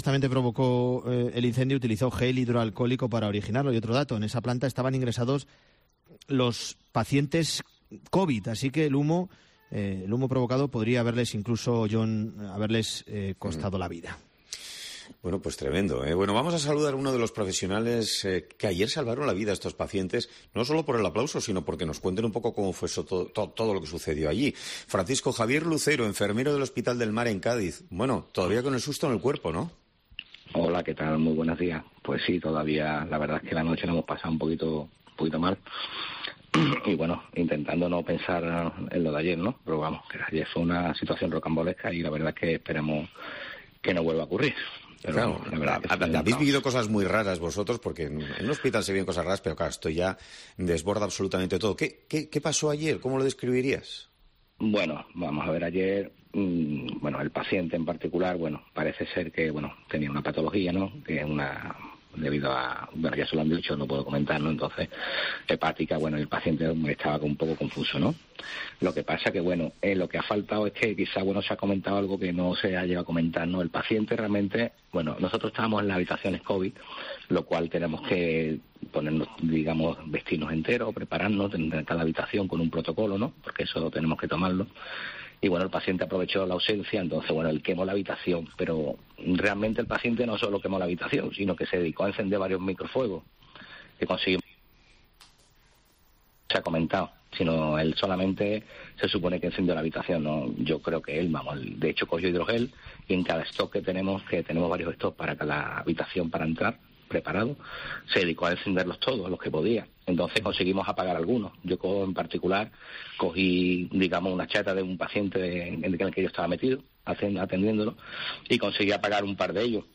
Testimonio de un enfermero